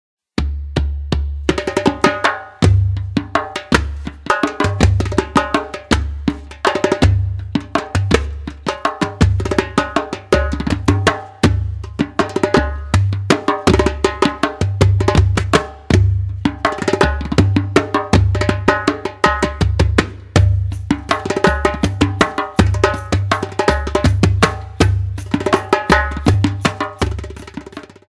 Percussion and Piano Music